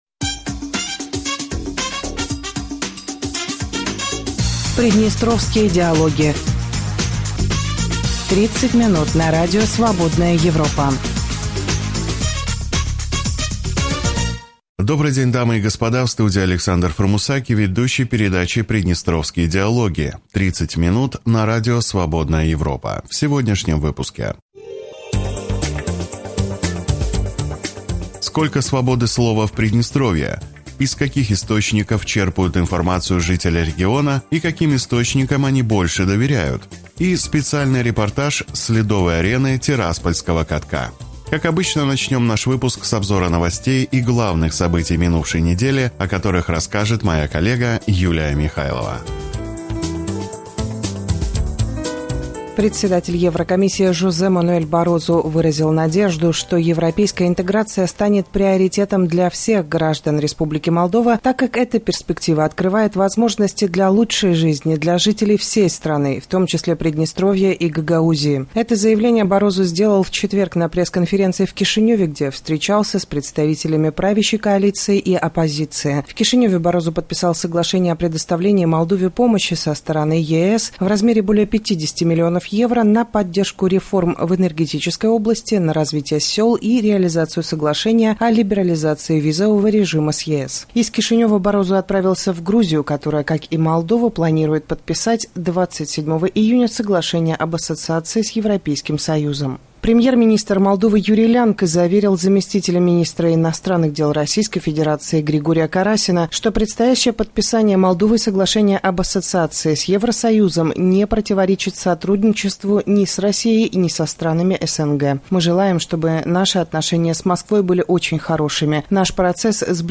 Из каких источников черпают информацию жители региона и каким источникам они больше доверяют? И… специальный репортаж с ледовой арены тираспольского катка.